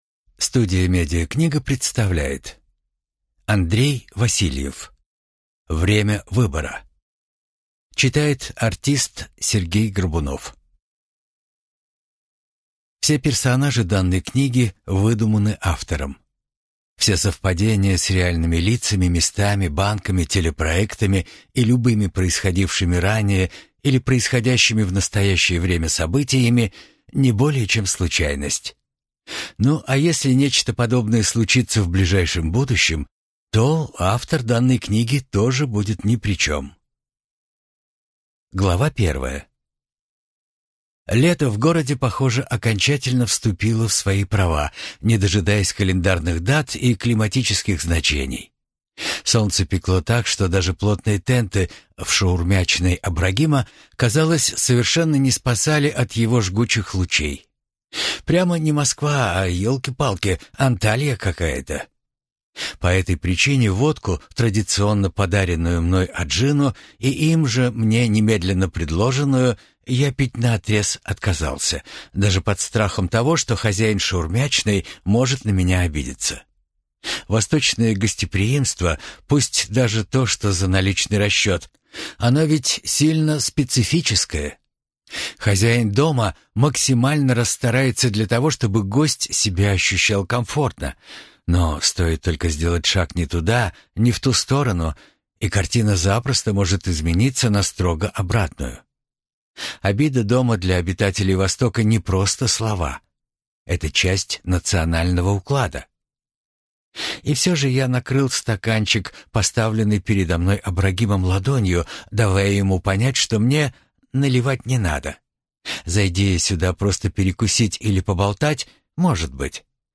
Аудиокнига Время выбора | Библиотека аудиокниг